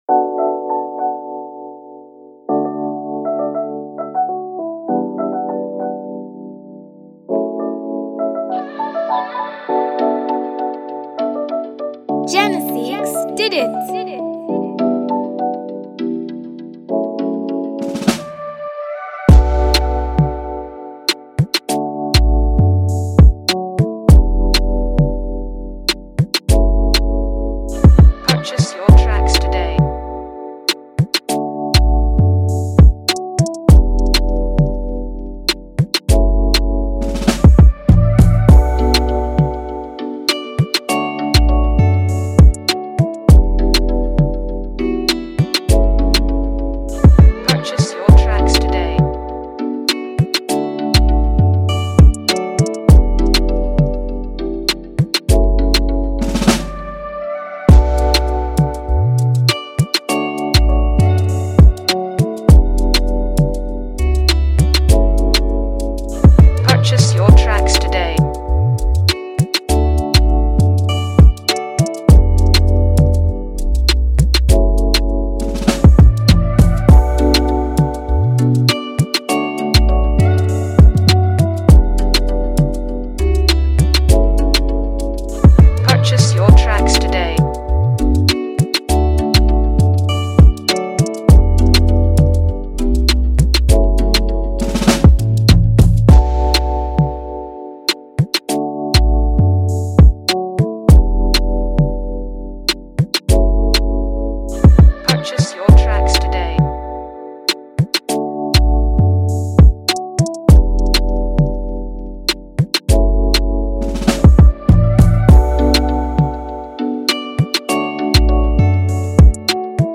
free beat